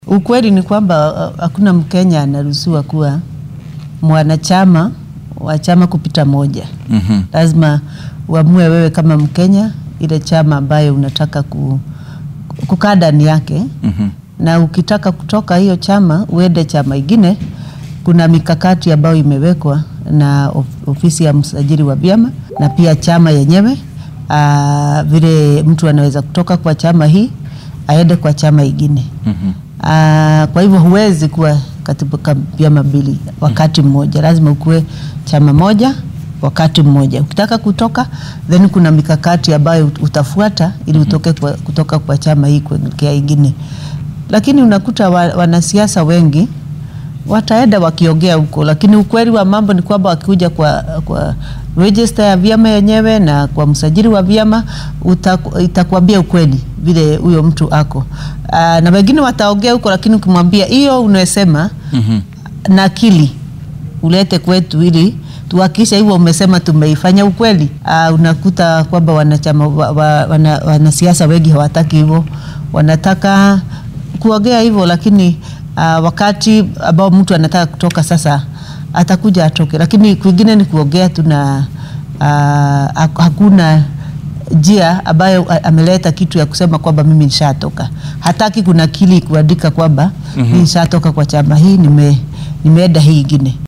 DHAGEYSO:Haweeneyda xisbiyada diiwaangelisa oo ka hadashay siyaasiyiinta xisbiyada iskaga baxa